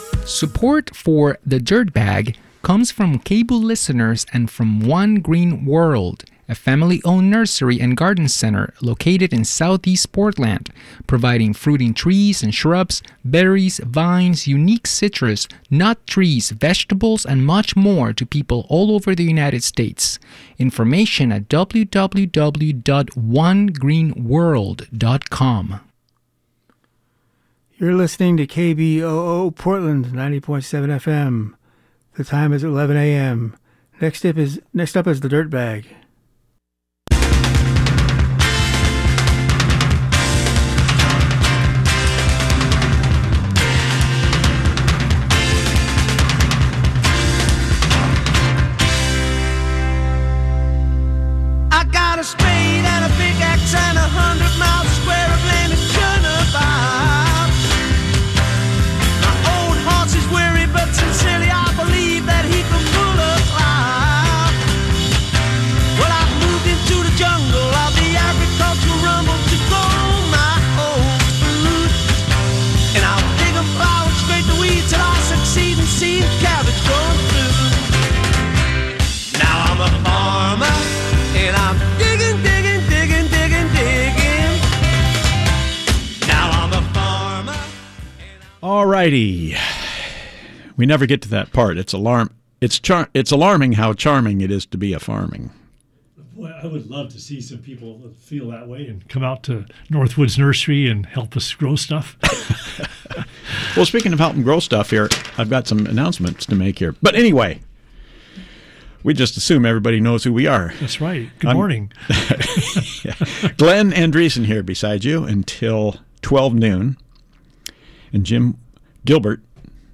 are back in the studio for another live show